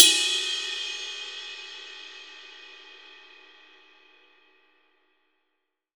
RIDE 1.wav